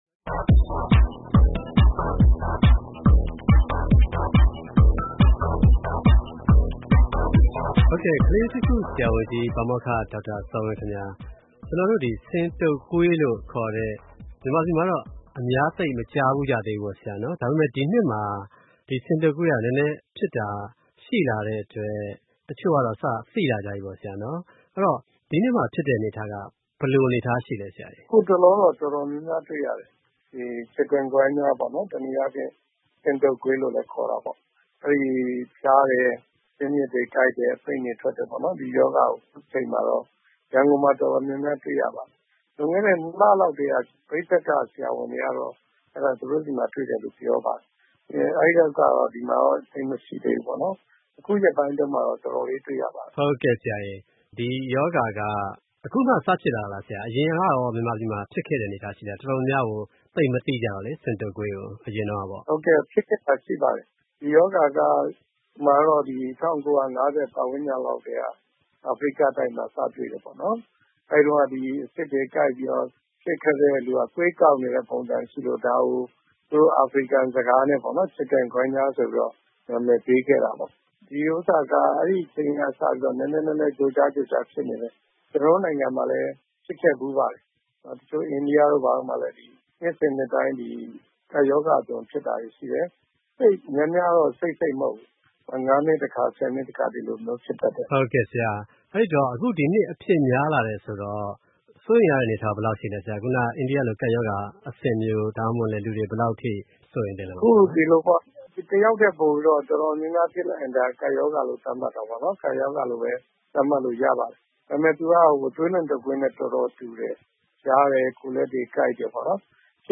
by ဗွီအိုအေသတင်းဌာန